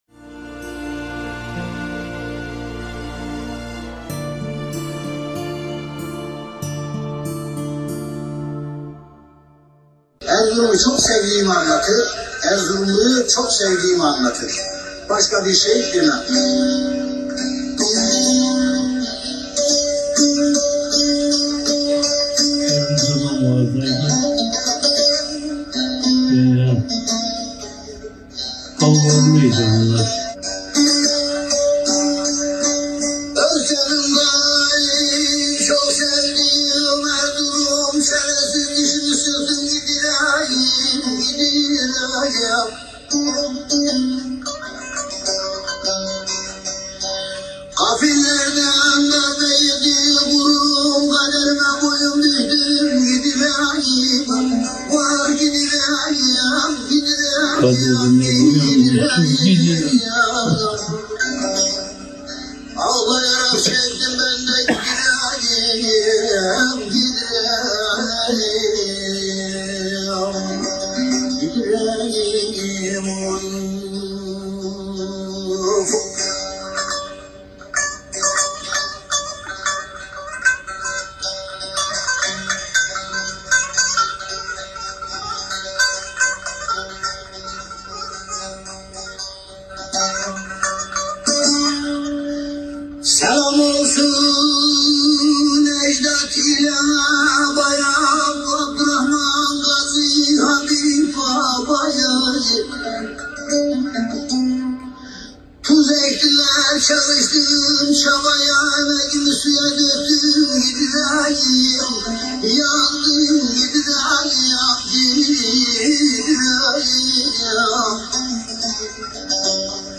Muhterem Hocaefendi bazı günler bir şiir okur bazen de bir türküden bir iki mısrayı terennüm ederlerdi.